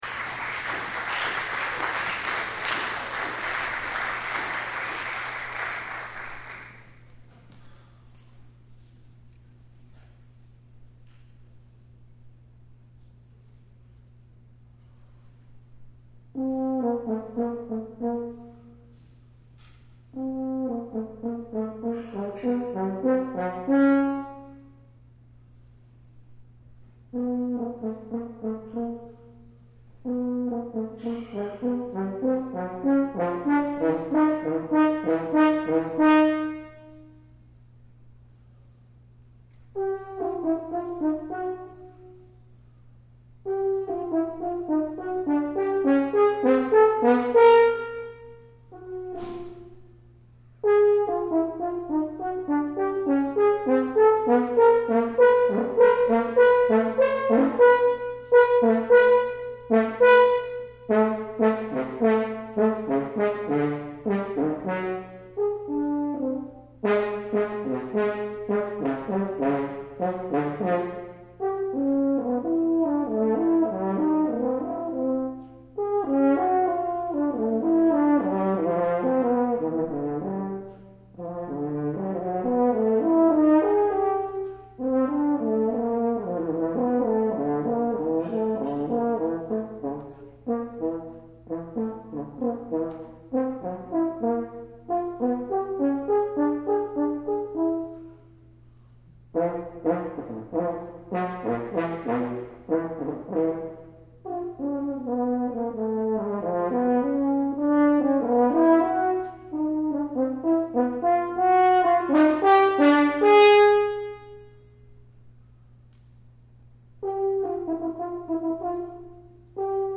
Mischief (horn solo)
Mischief is a composition full of playfulness that portrays the sense of humor through horn solo.